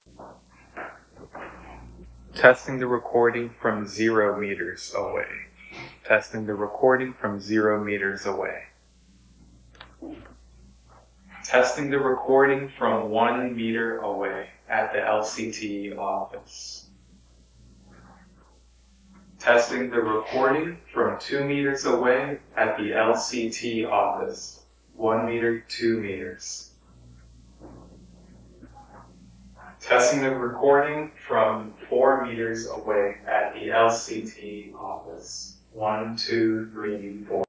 Demo 2 – Small Conference Room
After LCT dereverberation and denoise:
Audio8_lct_result_iphone.wav